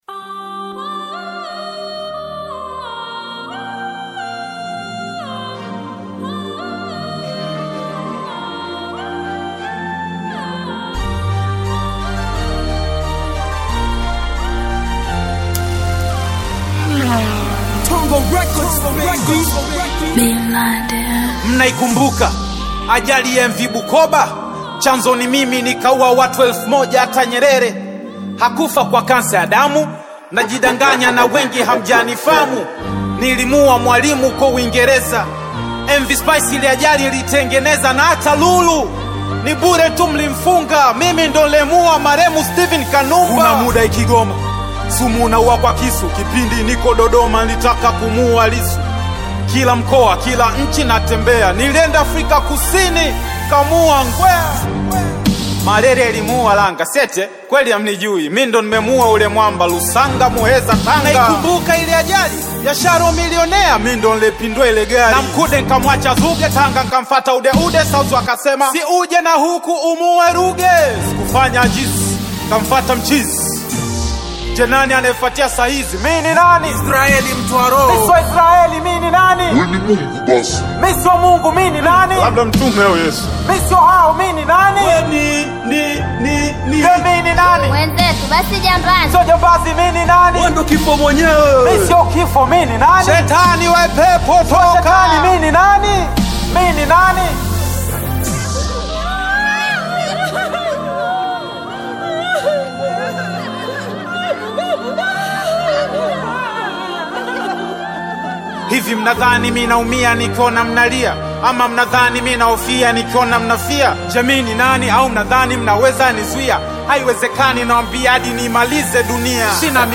is a renowned hip-hop recording artist